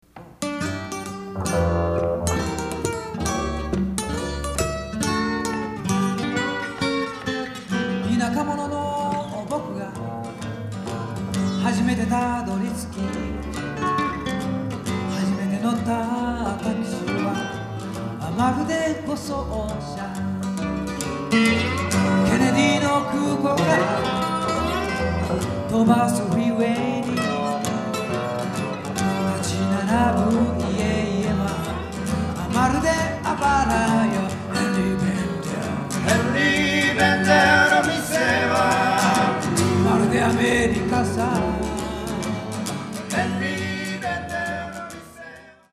ジャンル：フォーク/ポップス